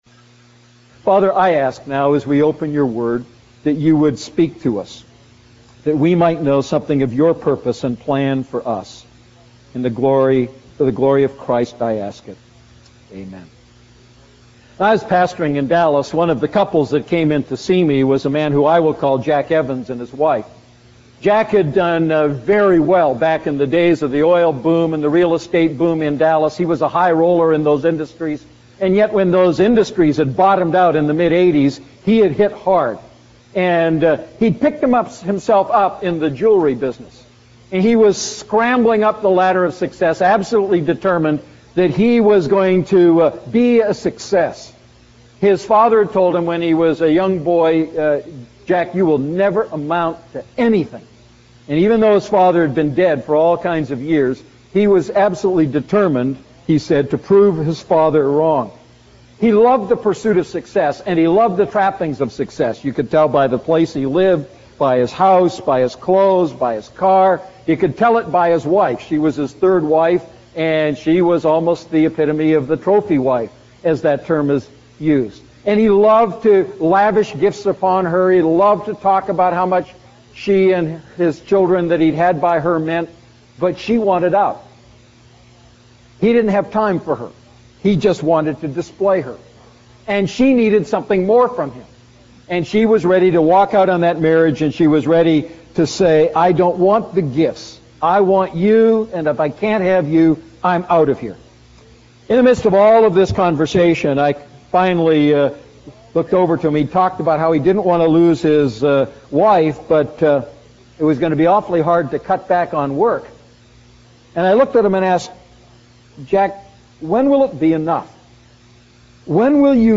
A message from the series "Defining Moments."